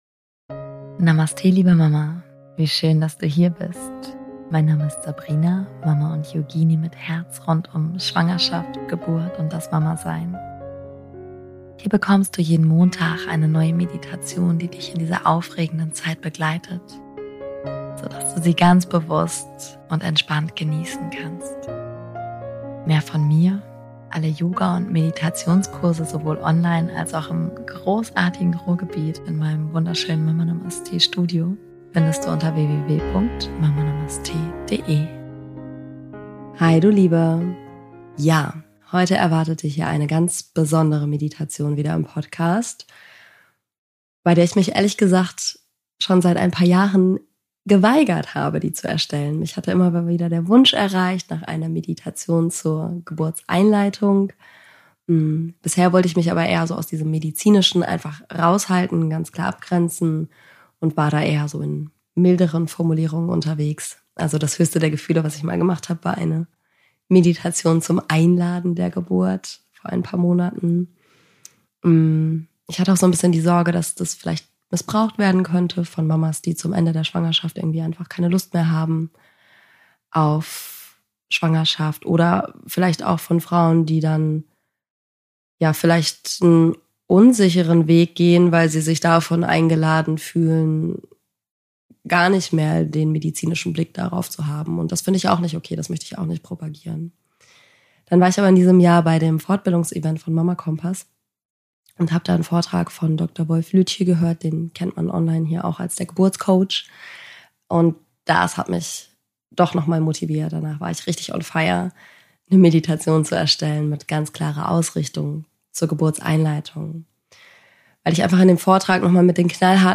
#243 - Einleitung der Geburt - Meditation ~ Meditationen für die Schwangerschaft und Geburt - mama.namaste Podcast